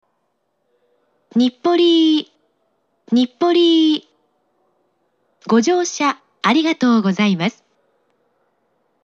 ３番線到着放送
発車メロディー（ｓｕｎｒｉｓｅ）
現在よりも発車メロディーの音質が悪かったです。
また、到着放送の言い回しが現在と違いました。